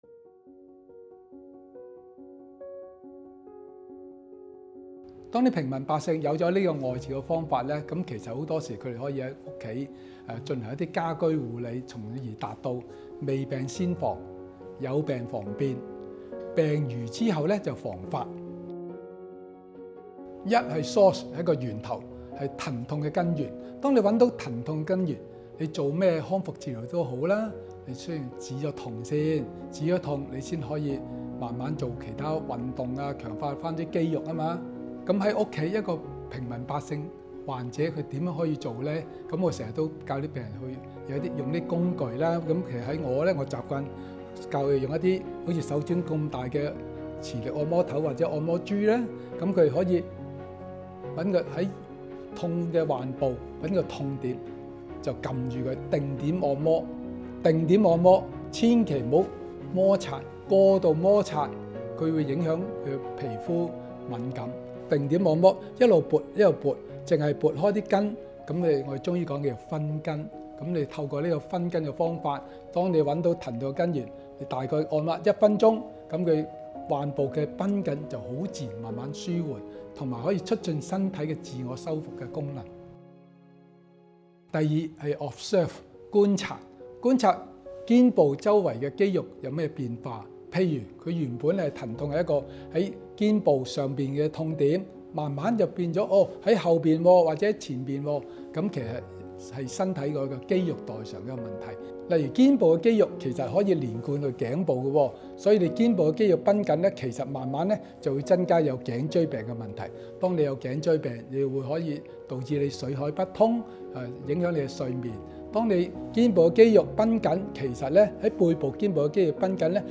5分鐘居家護肩運動-聲音導航
跑出腦動力_01_健肩_VO.mp3